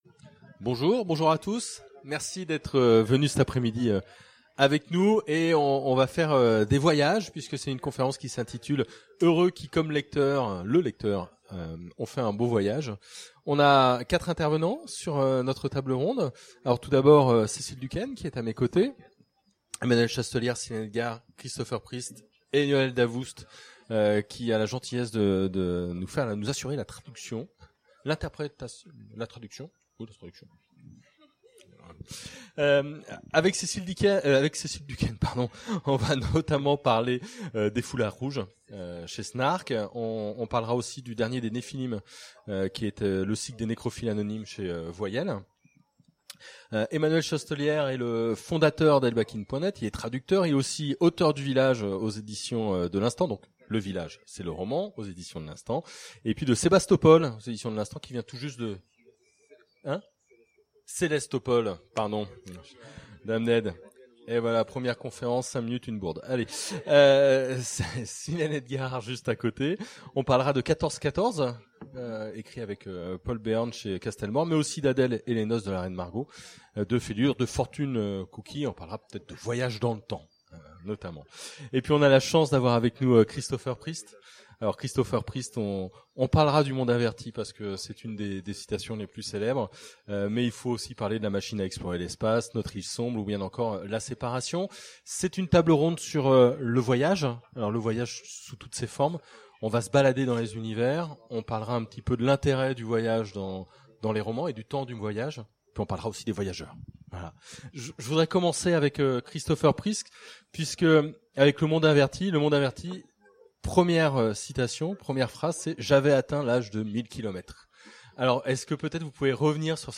Mots-clés Voyages Conférence Partager cet article